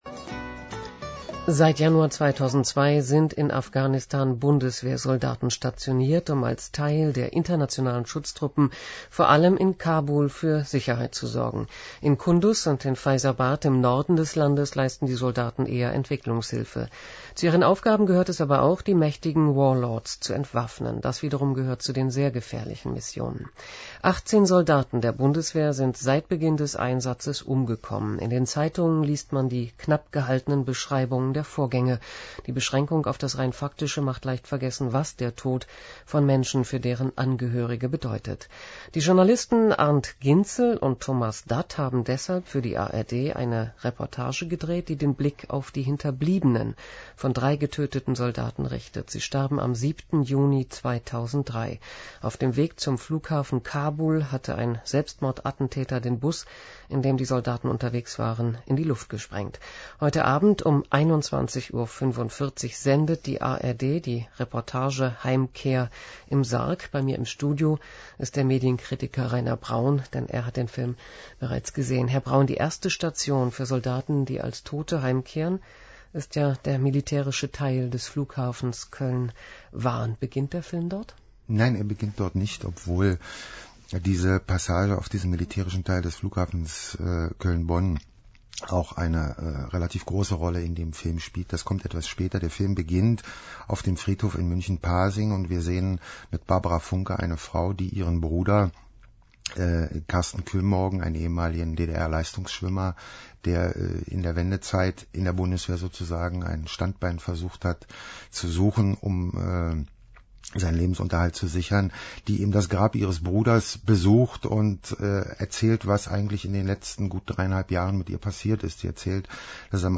Rezension im DRadio.